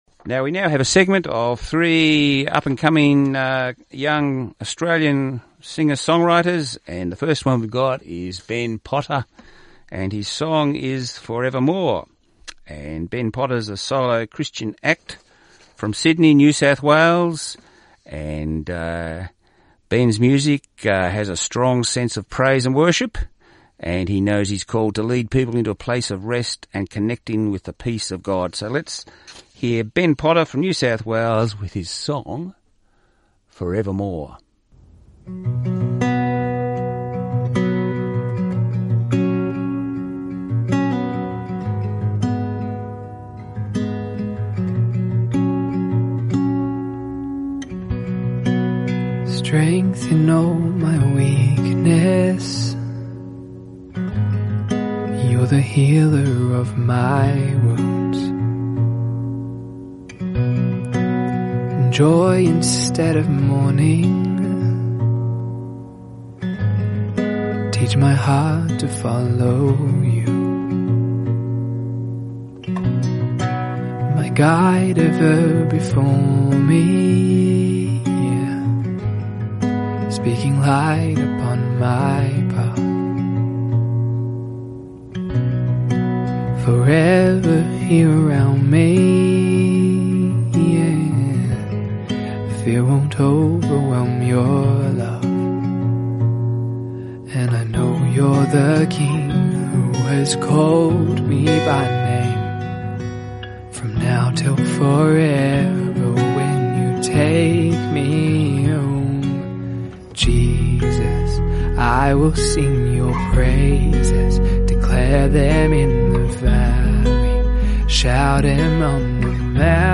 Australian Christian Music